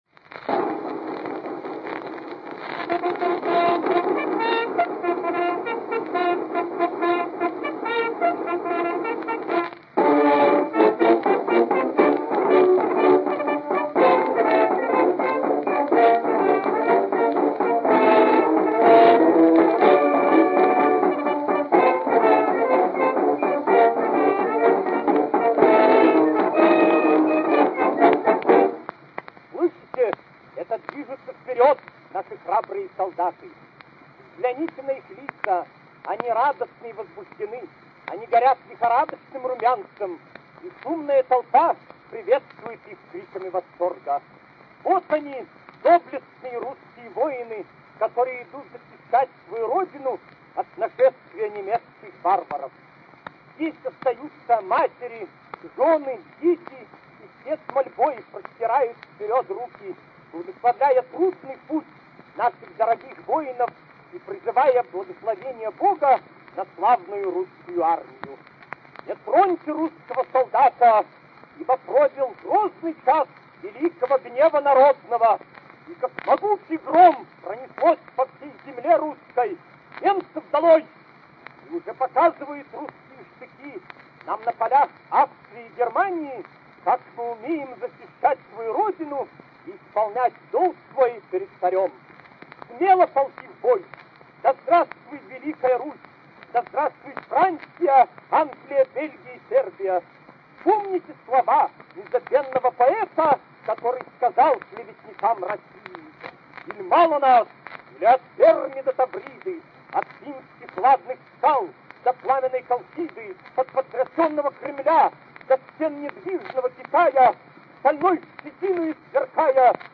Прекрасно написанный и с блеском произнесённый текст обрамляют фрагменты очень популярного тогда марша «Дни нашей жизни» (кстати сказать, эти фрагменты, «По улицам ходила большая крокодила», уж точно имеют одесское происхождение, но это совсем другая история, к которой мы в своё время непременно вернёмся).